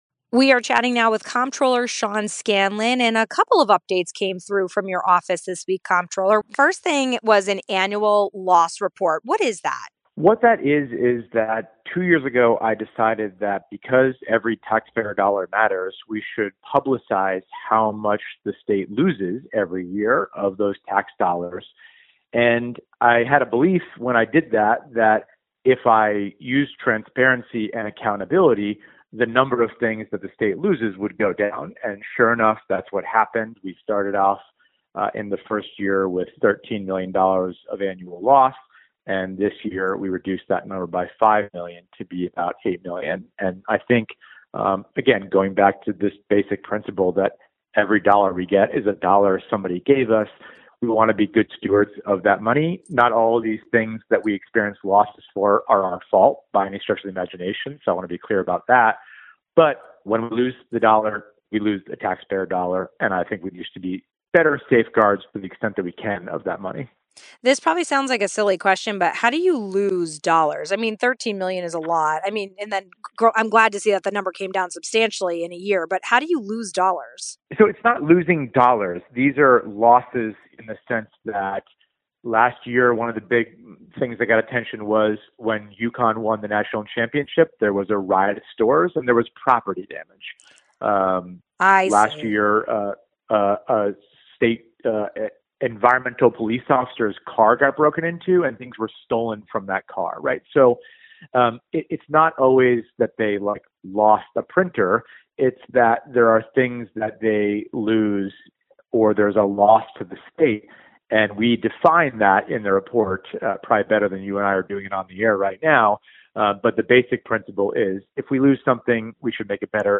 Big win for para-educators across our state: it was announced that $10 million dollars will be distributed to assist more than 73-hundred paraeducators with health insurance costs. We spoke with Comptroller Sean Scanlon about the news as well as a check on his annual losses report.